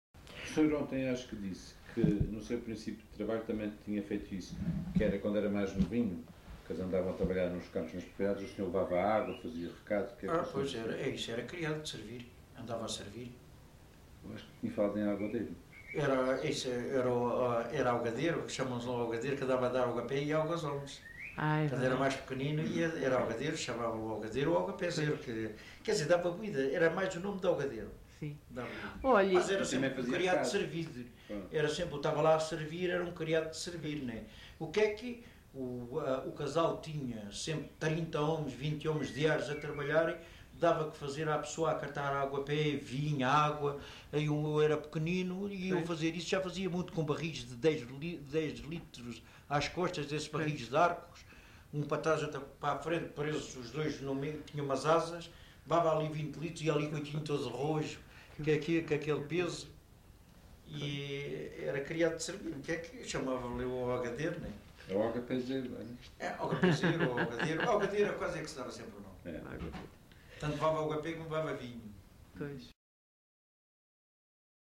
LocalidadeEnxara do Bispo (Mafra, Lisboa)